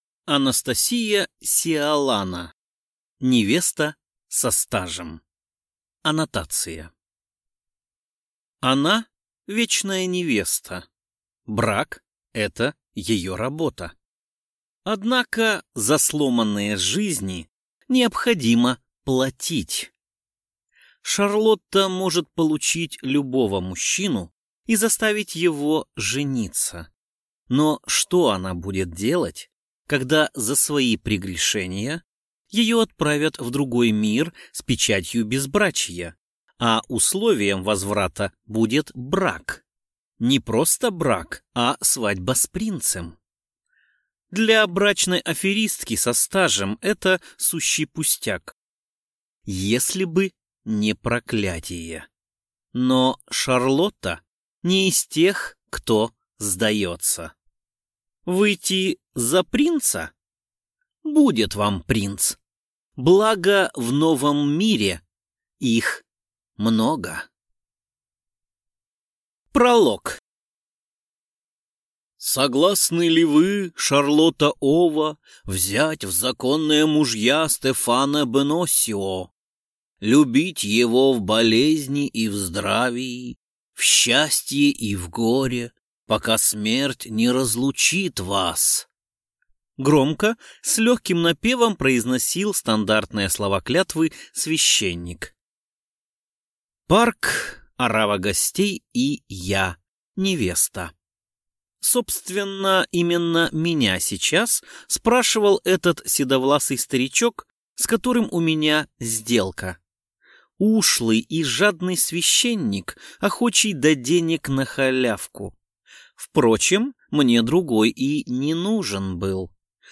Аудиокнига Невеста со стажем | Библиотека аудиокниг